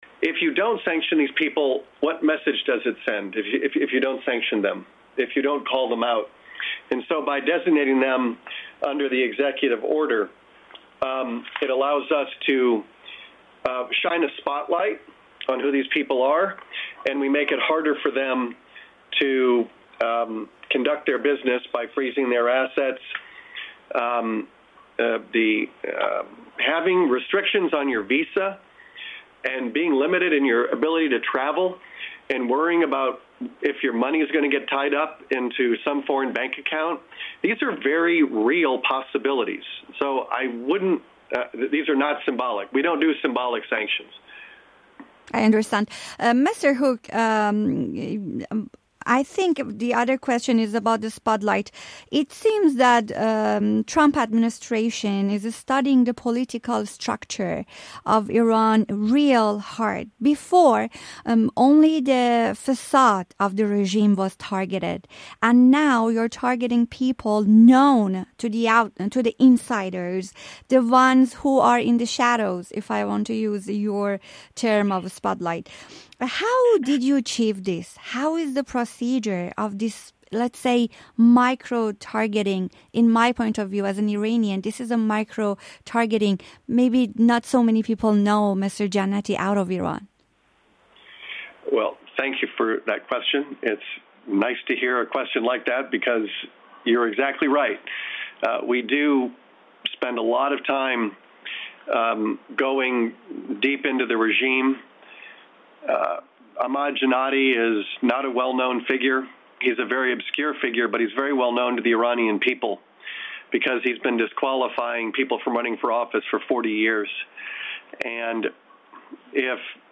Interview with Brian Hook U.S. Special Representative For Iran